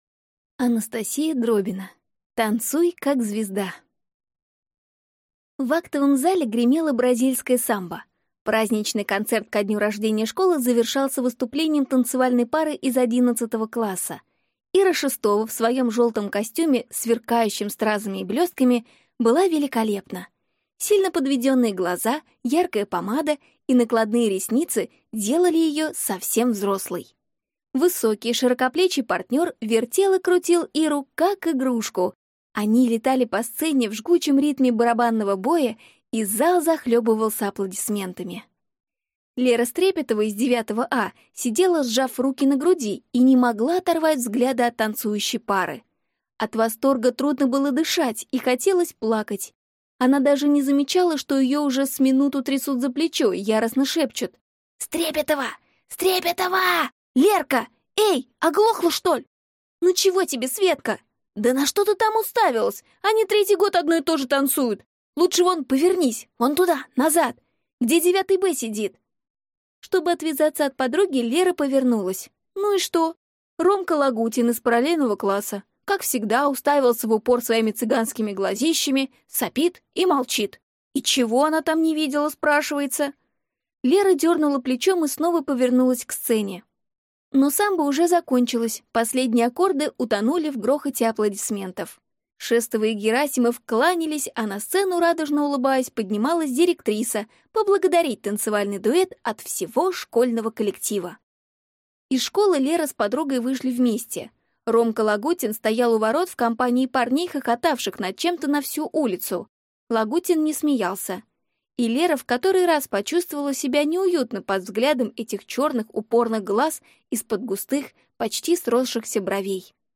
Аудиокнига Танцуй как звезда | Библиотека аудиокниг